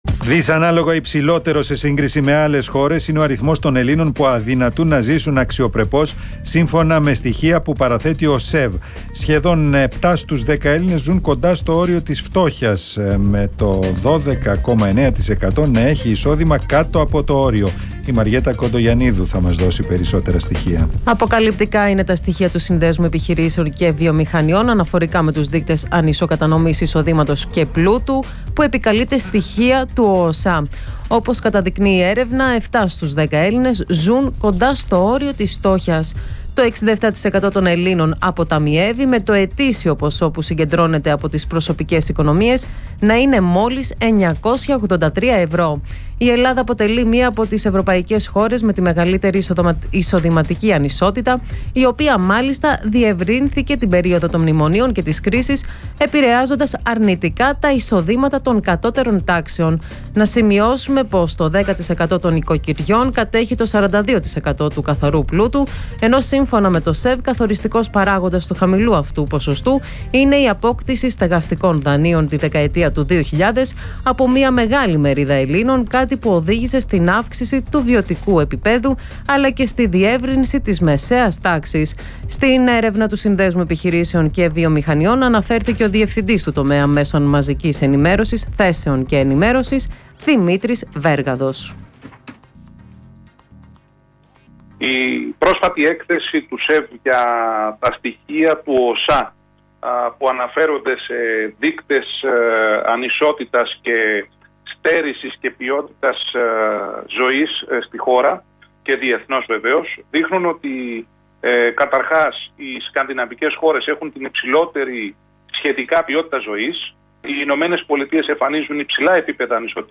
Συνέντευξη
στον Ρ/Σ REAL FM